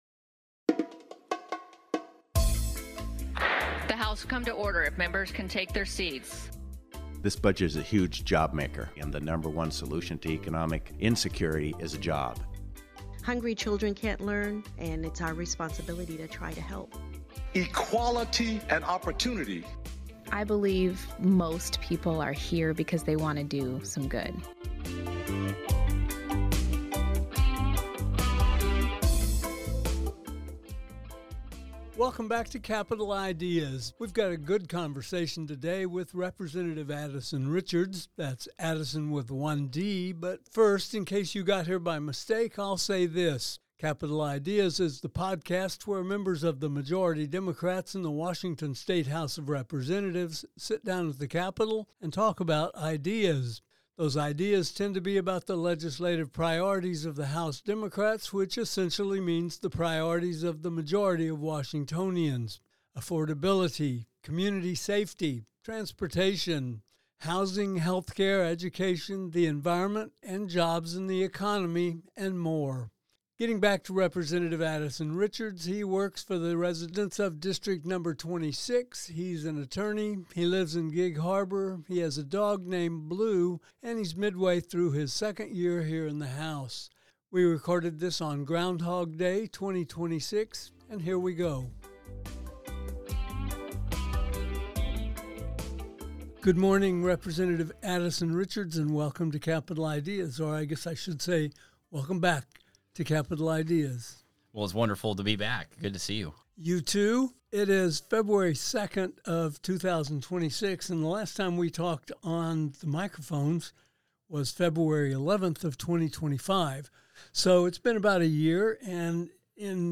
Today, Rep. Adison Richards (D-Gig Harbor) makes an encore appearance on Capitol Ideas, midway through his second legislative session
He was our guest almost exactly a year ago, less than a month into his new job as a state lawmaker. We invited him back to catch up on his trajectory as a representative, to hear about his key bills, and to check on the health and happiness of his faithful dog, Blue.